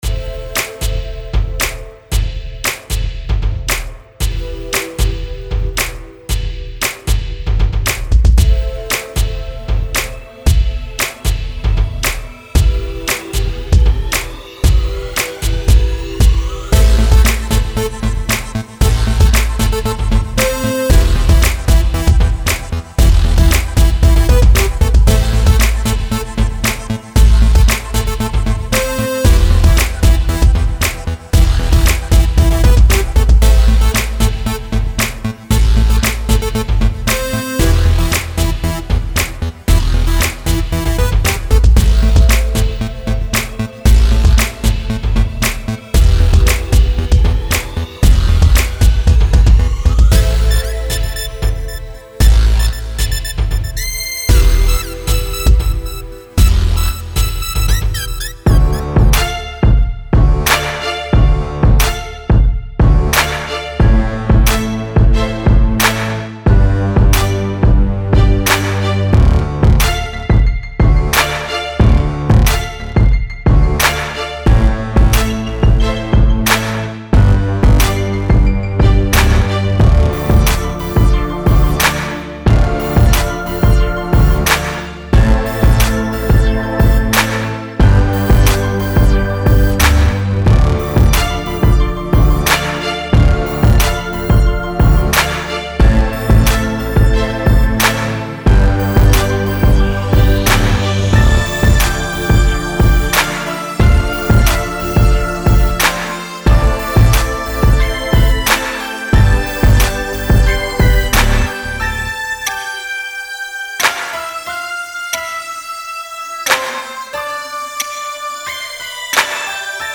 3. EDM
它适用于所有城市类型，例如嘻哈，RnB，Dirty South，Modern Pop，Electro等。
•99个键和带有速度标记的循环
•以70至115 BPM的速度循环•